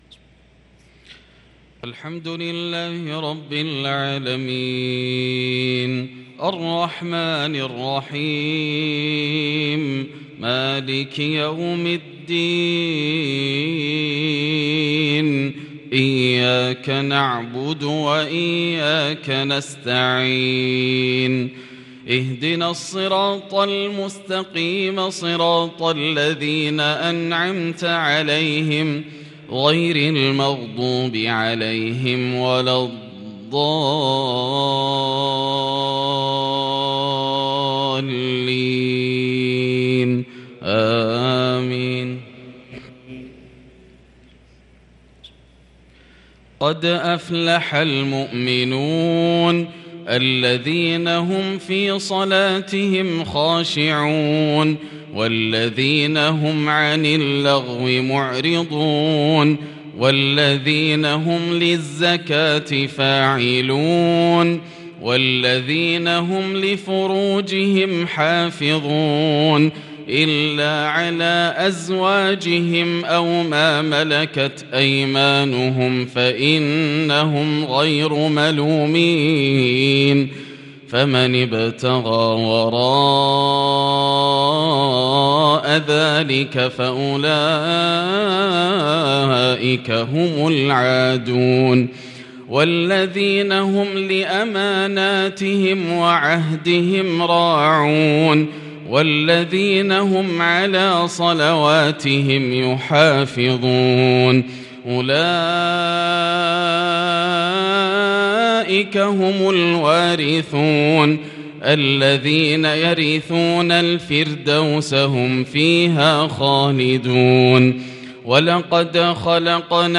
صلاة العشاء للقارئ ياسر الدوسري 13 ربيع الأول 1444 هـ